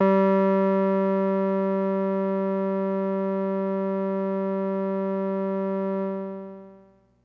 描述：通过Modular Sample从模拟合成器采样的单音。
标签： FSharp4 MIDI音符-67 Arturia-Microbrute 合成器 单票据 多重采样
声道立体声